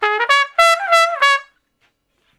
087 Trump Straight (Db) 07.wav